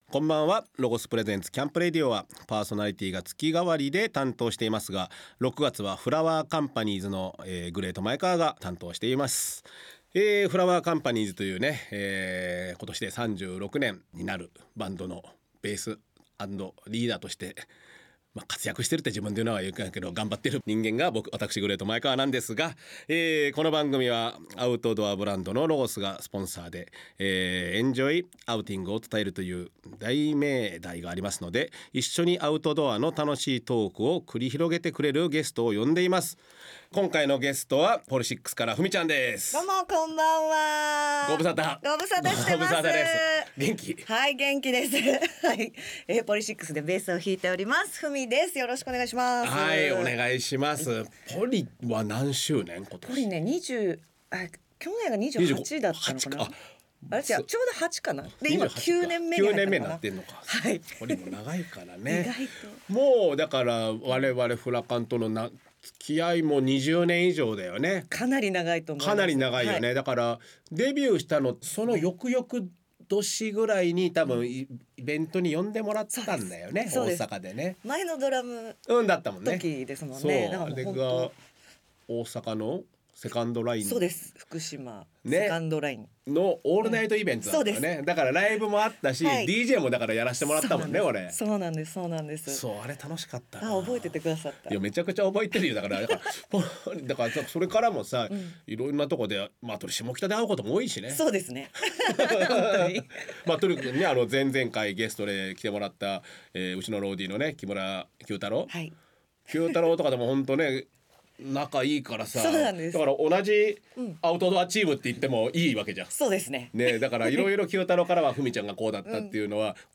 毎回、様々なジャンルの方々をゲストに迎え、アウトドアをはじめ、ゲストの専門分野や、得意なコト、夢中になっているコトなどをじっくり伺い、その魅力に迫る番組です。